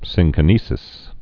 (sĭnkə-nēsĭs, -kī-, sĭng-)